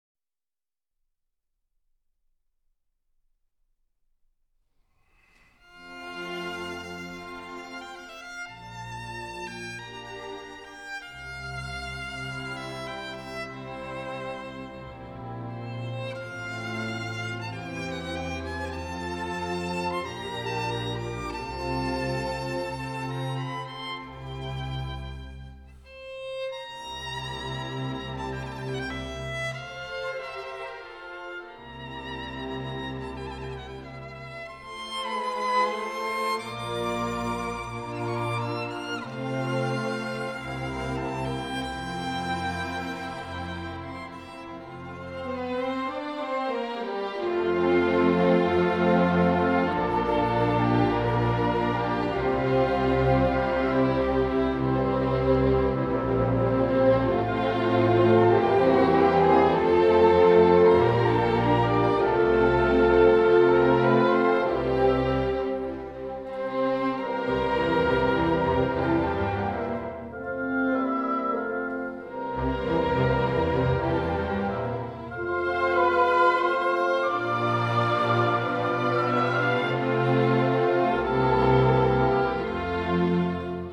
小提琴
古典音樂、發燒音樂
PER VIOLINO E ORCHESTRA
• N° 2 IN FA MAGG.